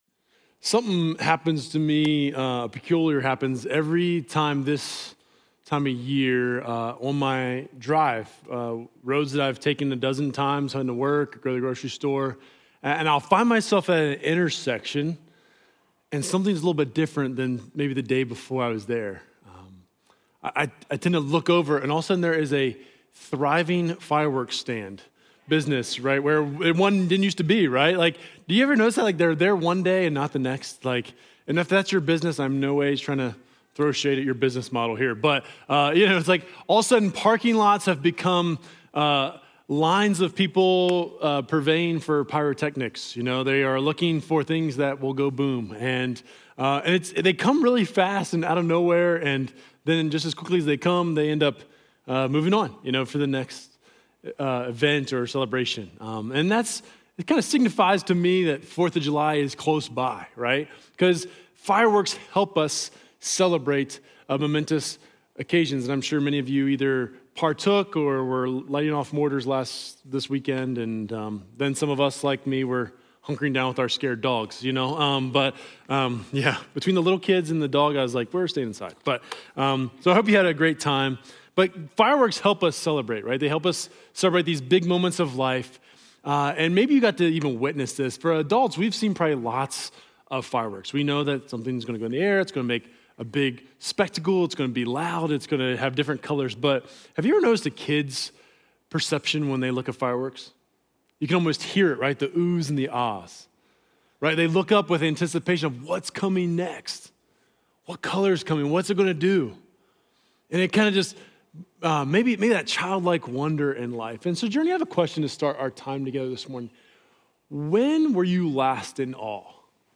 Journey Church Bozeman Sermons Summer In The Psalms: Awe + Wonder Jul 06 2025 | 00:33:27 1x 00:00 / 00:33:27 Subscribe Share Apple Podcasts Overcast RSS Feed Share Link Embed